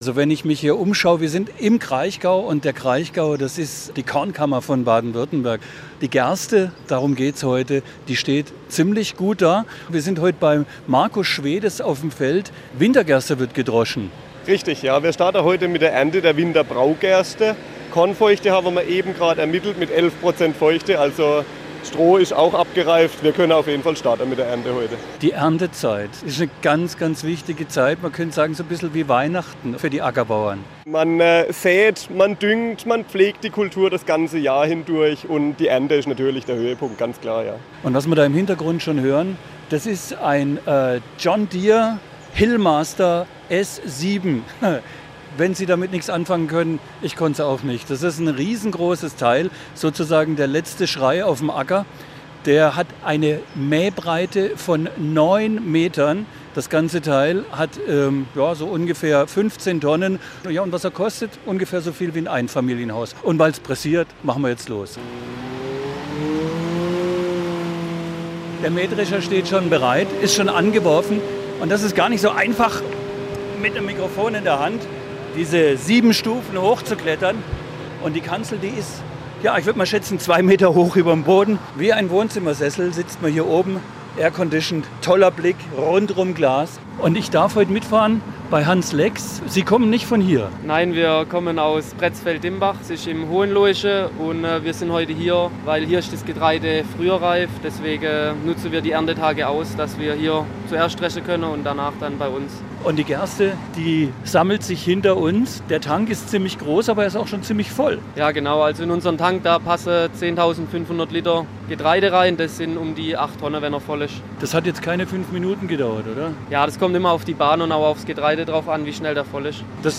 war zum Start der Getreideernte auf dem Feld - und hatte die Möglichkeit, auf dem Mähdrescher mitzufahren.